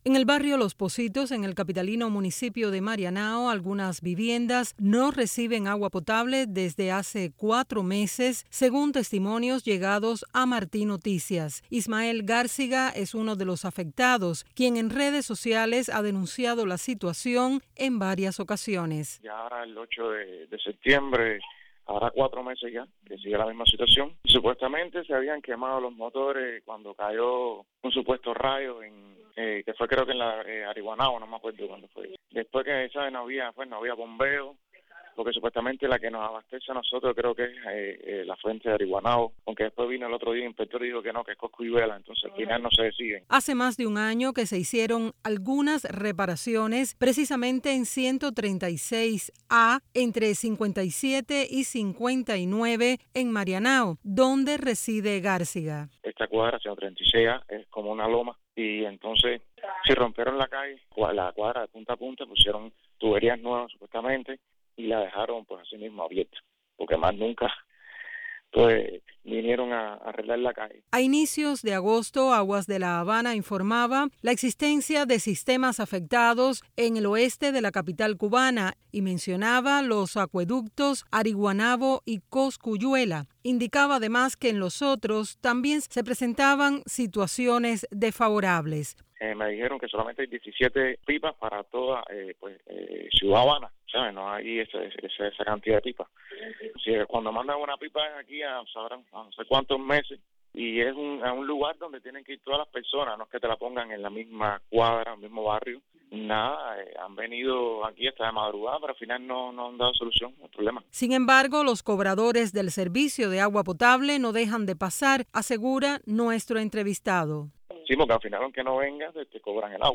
Otra afectada con el abastecimiento de agua en Los Pocitos, quien no quiso ser identificada, habló con Martí Noticias del tema.